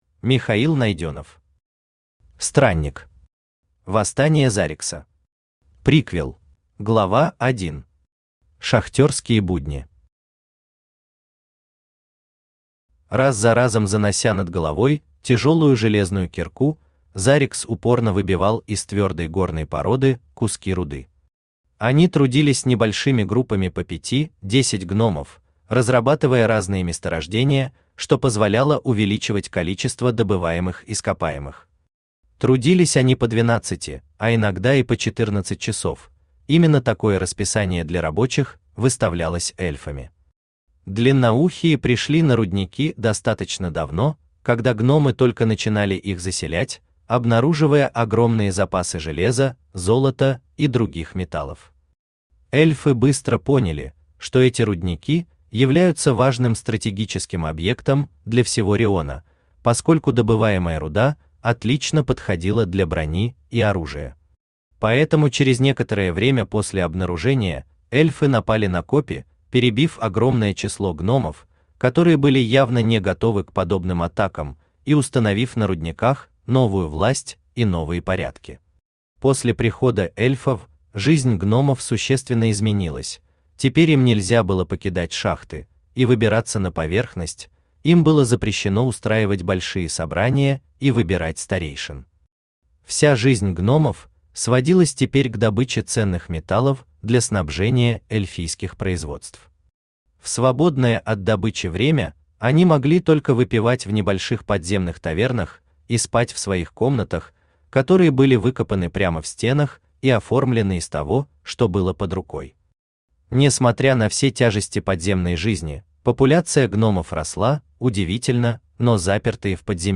Aудиокнига Странник. Восстание Зарикса. Приквел Автор Михаил Найденов Читает аудиокнигу Авточтец ЛитРес.